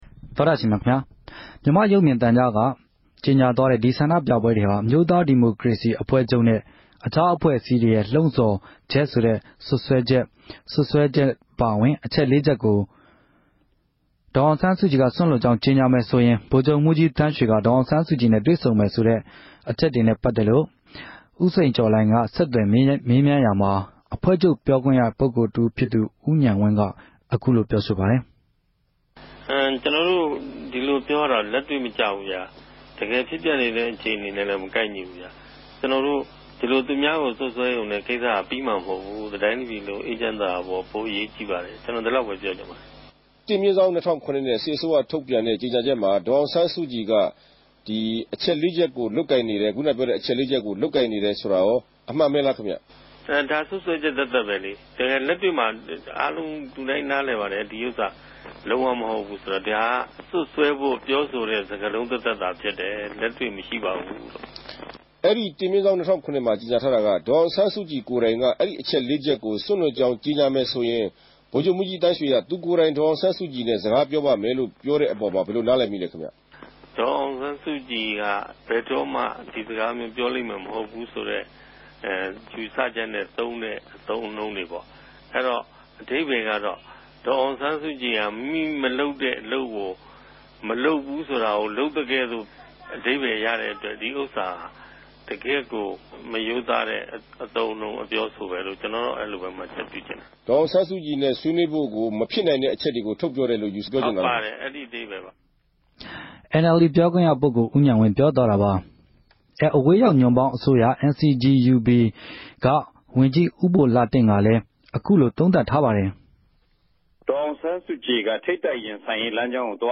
ူမန်မာ့႟ုပ်ူမင်သုံကားက အောက်တိုဘာ ၄ရက် ုကာသပတေးနေႛညက ေုကညာသြားတဲ့ စြပ်စြဲခဵက်တေနြဲႛ ဗိုလ်ခဵြပ်မြြးဋ္ဌကီး သန်းေ႟ကြ ဒေၞအောင်ဆန်းစုုကည်နဲႛ တြေႚဆုံ ဆြေးေိံြးမယ်ဆိုတဲ့အခဵက်တေအြပေၞ ဒီမိုကရေစီအဖြဲႚအစည်းတေရြဲ့ သဘောထားအူမင်ကို RFAက ဆက်သြယ်မေးူမန်းထားပၝတယ်။